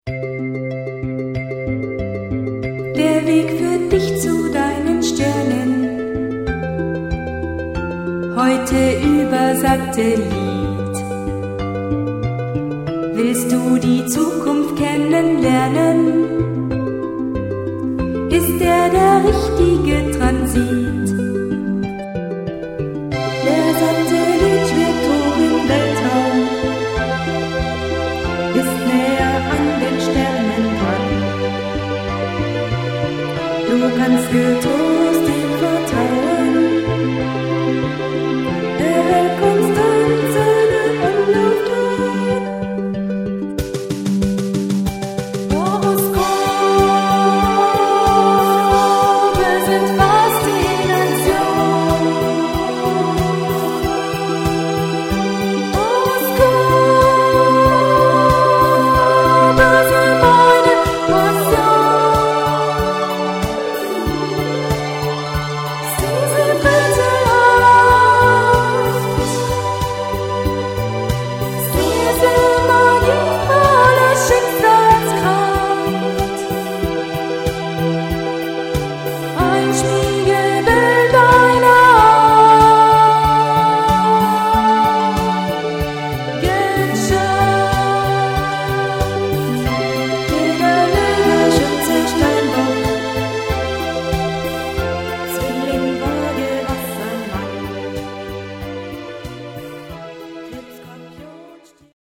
Aufgeführt an der FAKS München, Schlierseestr. 47,  im Januar 1998